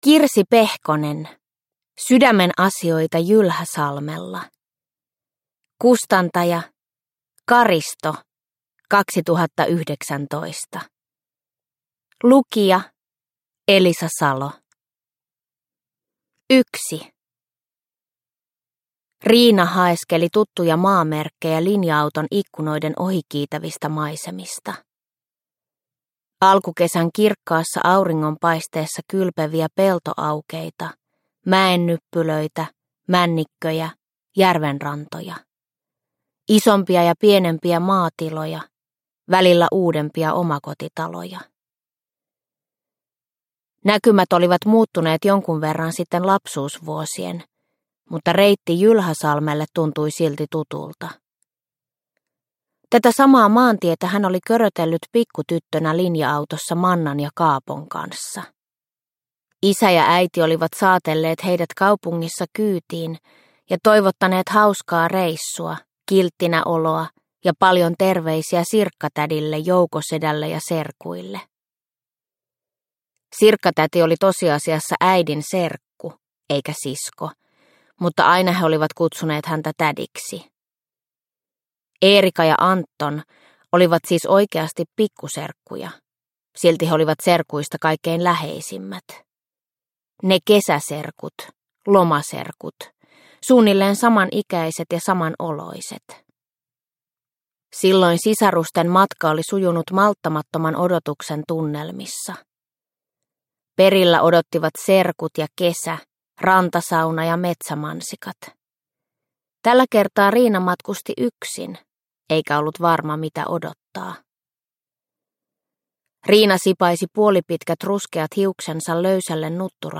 Sydämenasioita Jylhäsalmella – Ljudbok – Laddas ner